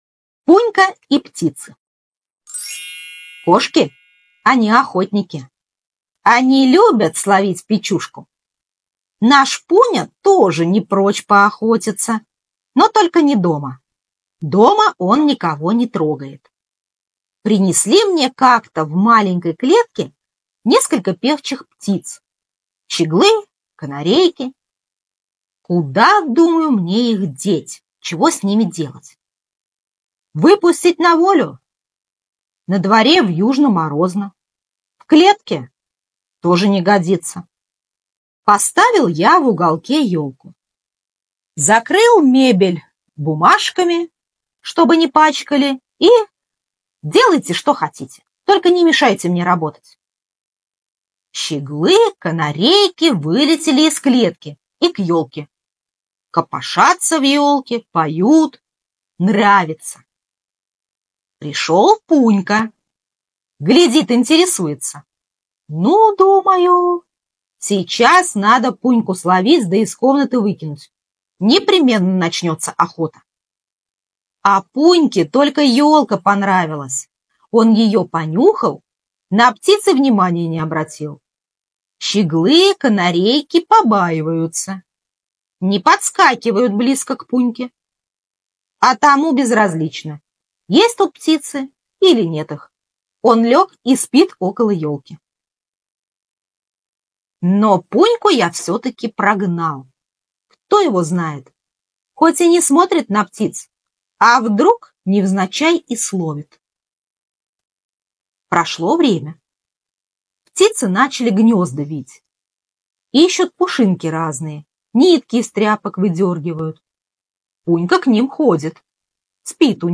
Аудиорассказ «Пунька и птицы»